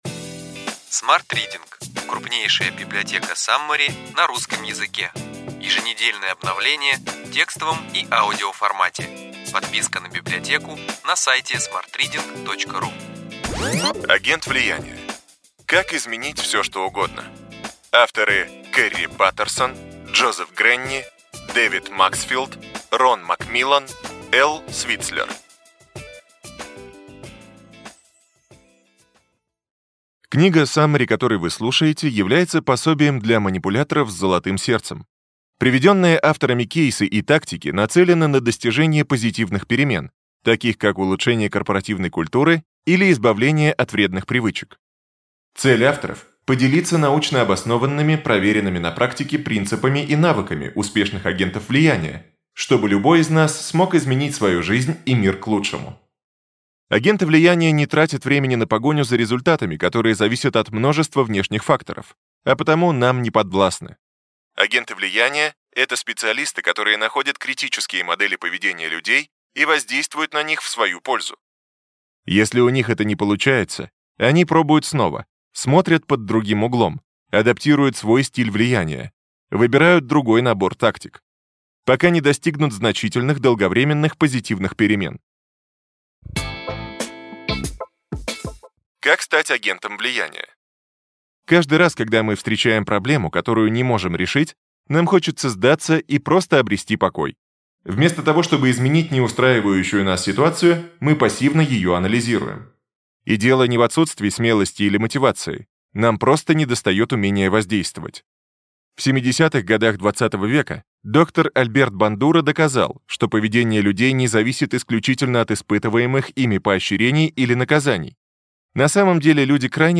Аудиокнига Ключевые идеи книги: Агент влияния. Как изменить все что угодно.